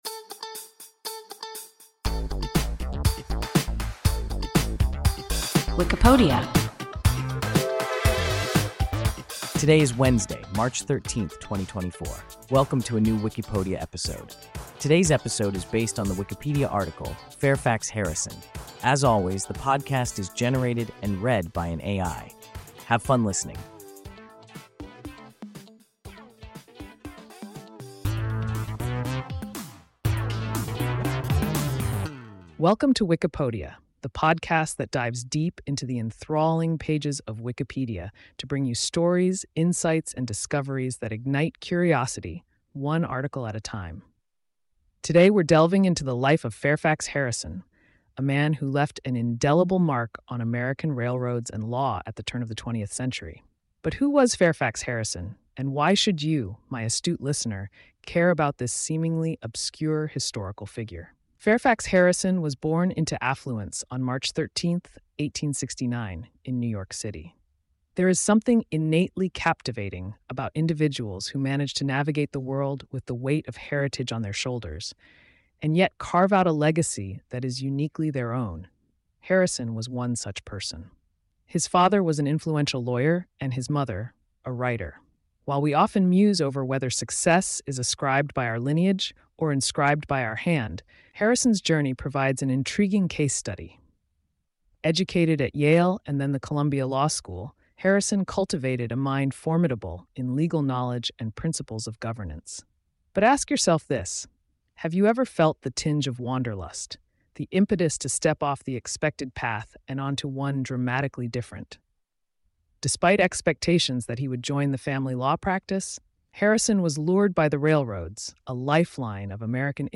Fairfax Harrison – WIKIPODIA – ein KI Podcast